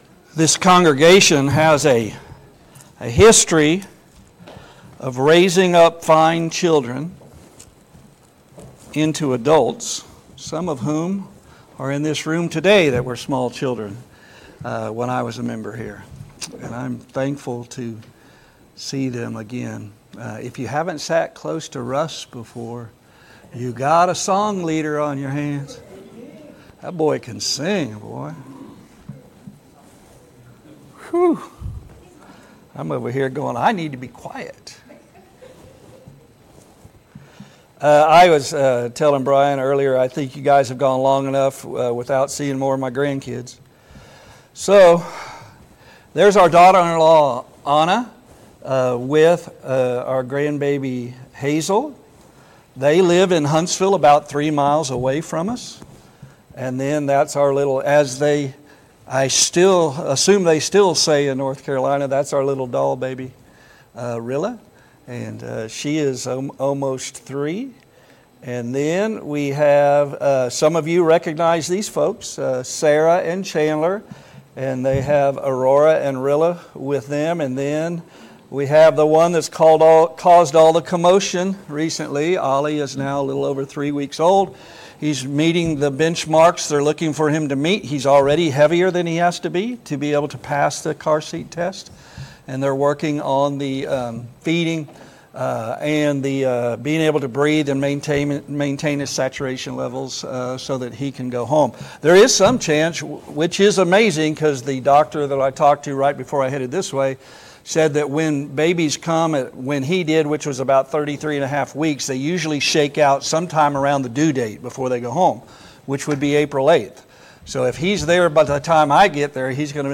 Mark 16:15-16 Service Type: Gospel Meeting « 5.